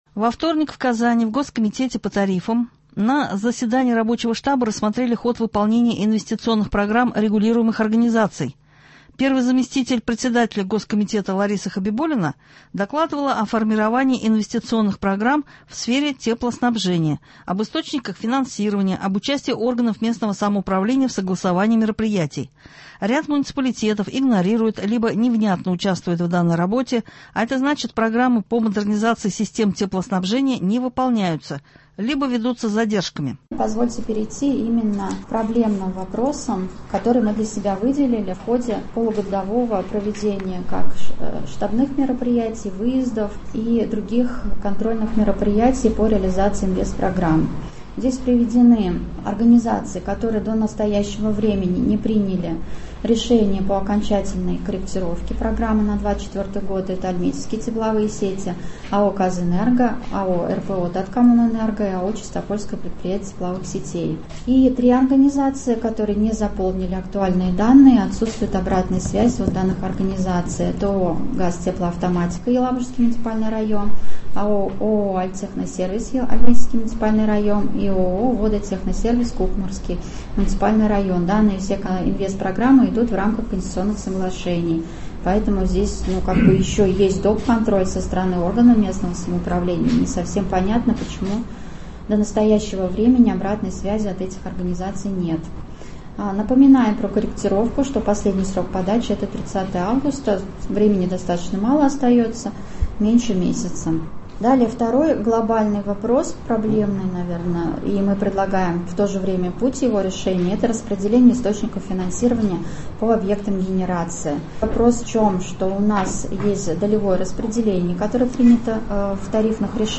Также в программе прозвучит запись с брифинга в Кабинете министров, где журналистам рассказали о том, как в Татарстане ведется модернизация систем ЖК сферы.